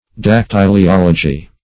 Search Result for " dactyliology" : The Collaborative International Dictionary of English v.0.48: Dactyliology \Dac*tyl`i*ol"o*gy\, n. [Gr. dakty`lios finger ring + -logy.]